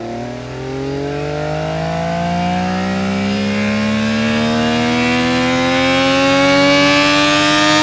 rr3-assets/files/.depot/audio/Vehicles/f1_01/f1_01_accel.wav
f1_01_accel.wav